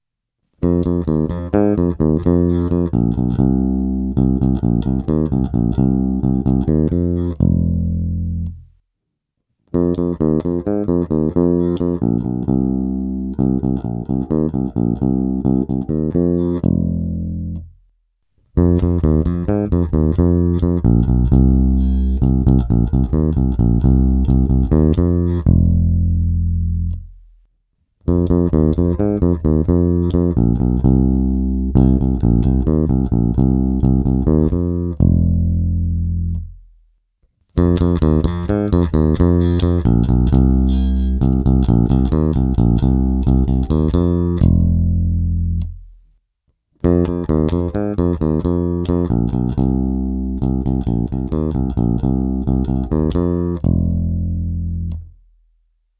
(1) korekce ve střední poloze
(2) naplno přidané středy
(3) naplno přidané basy
(6) všechno naplno
Vliv korekcí – normalizováno jednotlivě
Jak si můžete všimnout, největší vliv na nárůst signálu mají středy a pak samozřejmě když se spojí všechno.